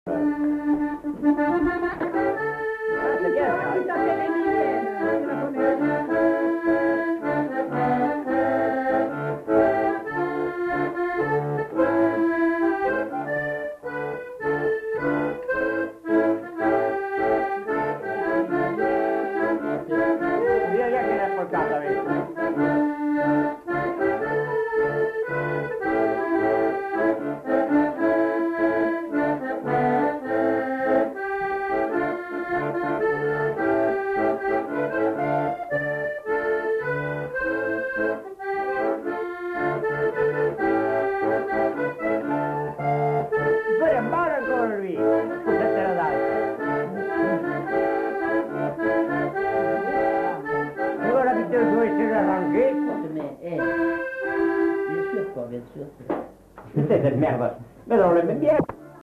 Aire culturelle : Lugues
Lieu : Pindères
Genre : morceau instrumental
Instrument de musique : accordéon diatonique
Danse : tango